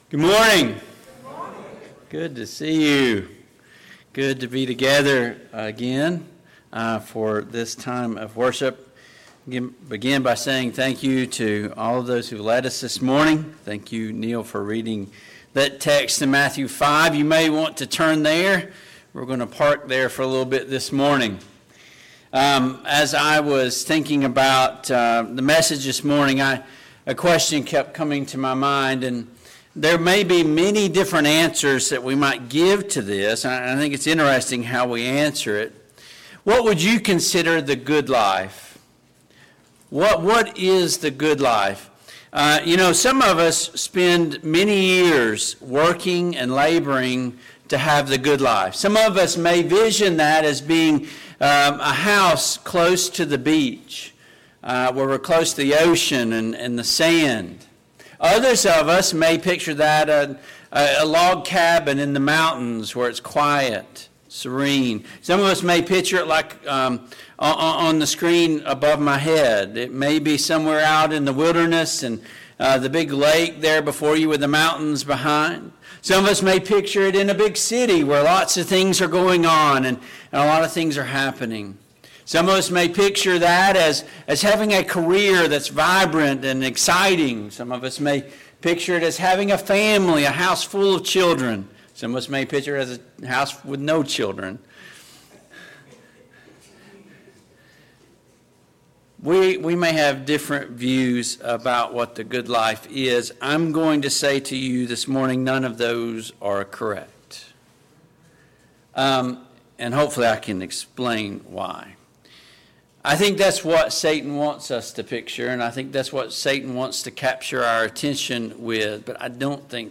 Matthew 5:1-12 Service Type: AM Worship Download Files Notes « 5.